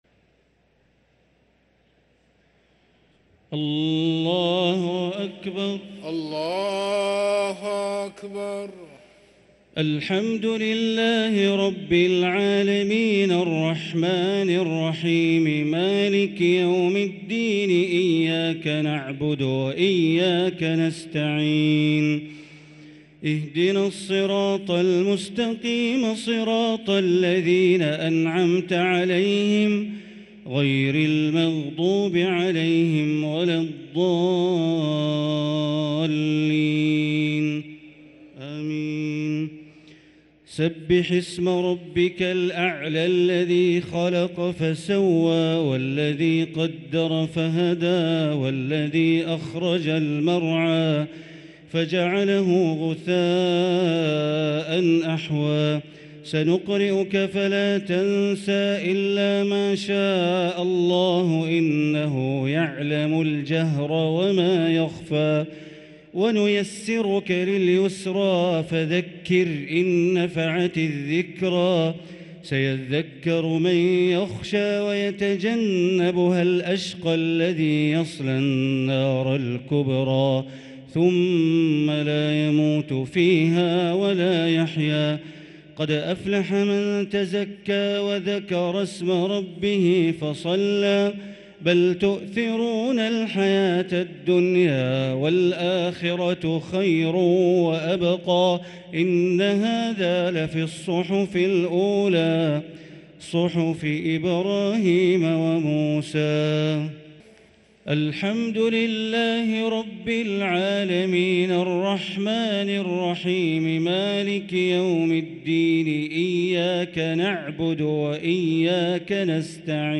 صلاة التراويح ليلة 4 رمضان 1444 للقارئ بندر بليلة - الشفع والوتر - صلاة التراويح